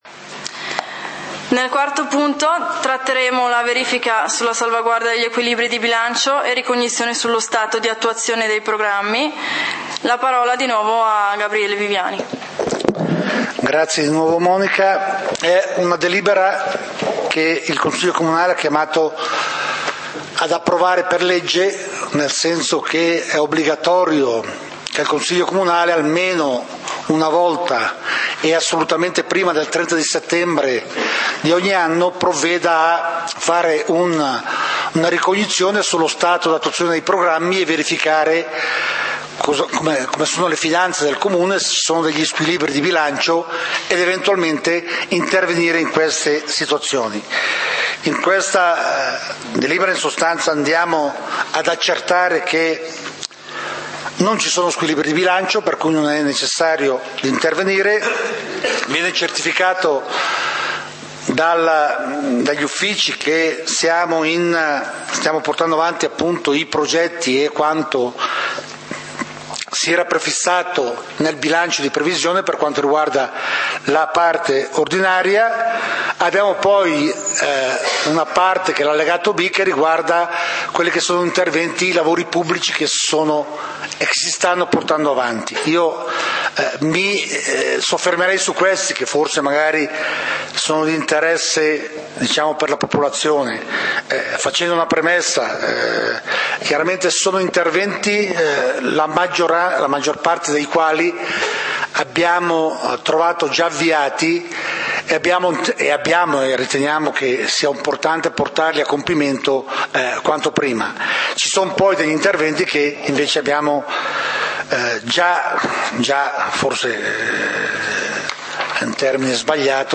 Punti del consiglio comunale di Valdidentro del 27 Settembre 2012